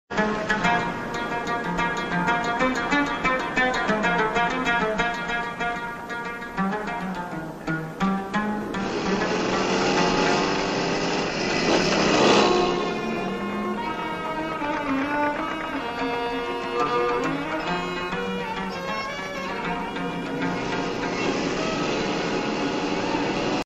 suasana kota Banjarmasin tempo dulu sound effects free download
suasana kota Banjarmasin tempo dulu tahun 1981